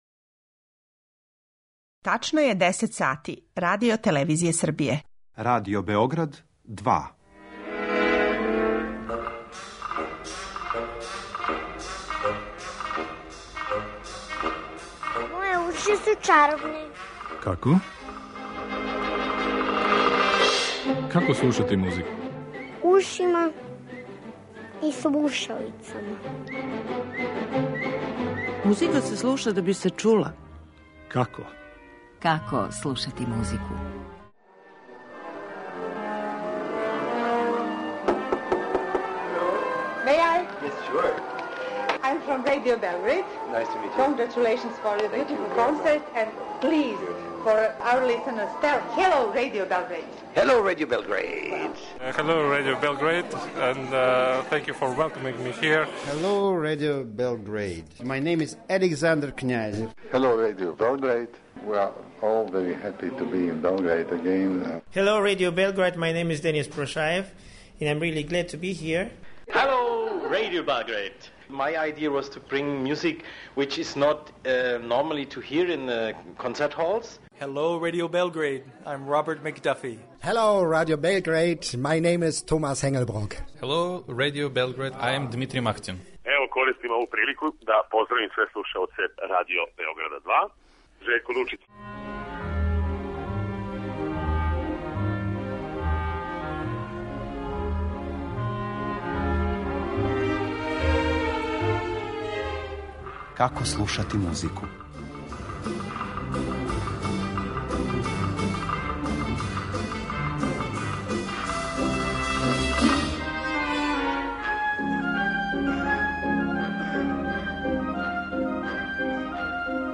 Овог месеца имаћете прилику да у оквиру циклуса емисија Како слушати музику чујете многе наше и иностране музичке уметнике који су у кратким изјавама описивали како слушају музику, која су им омиљена дела и који су им омиљени аутори, шта мисле о музичком укусу уопште и шта слушање музике значи за њихову каријеру и уметничко деловање.